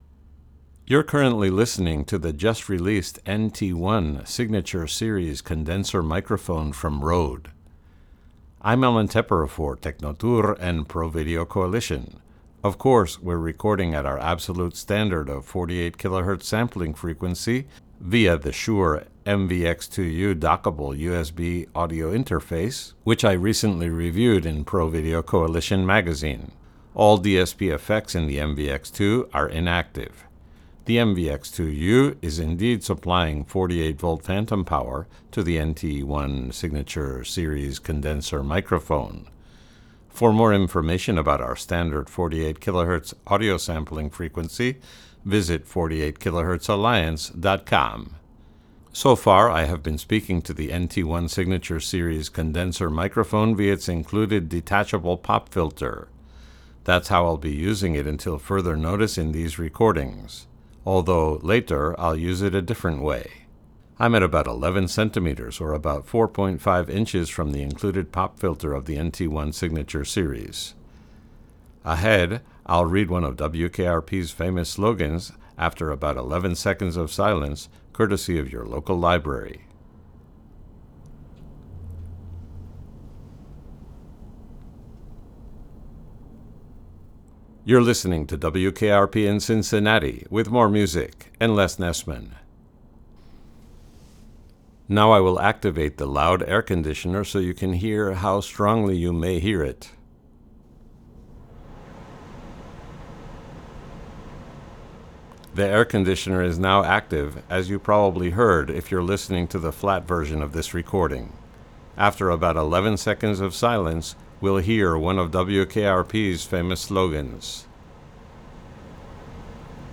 Test recording 1 (flat) with standard included pop filter
Especially if you listen with headphones, you will indeed hear some of the room and reverb.
NT-1-Signature-Edition-standard-pop-filter-normaliszed.wav